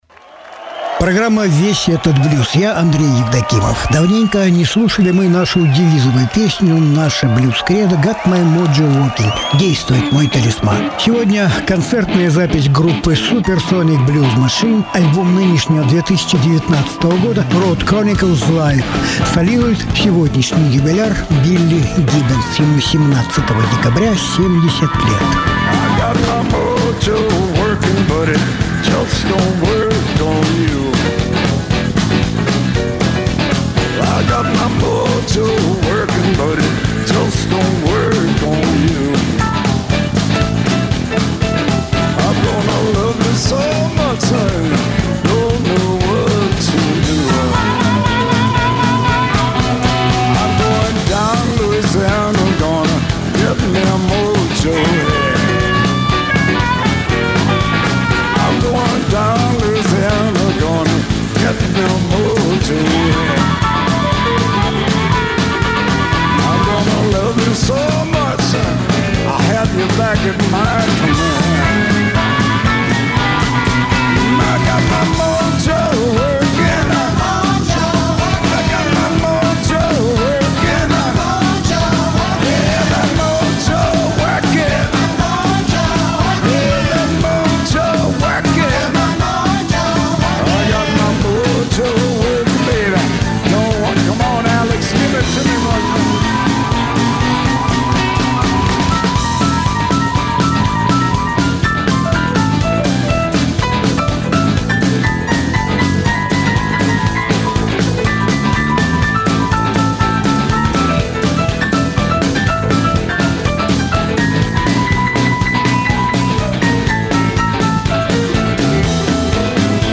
Жанр: Блюзы и блюзики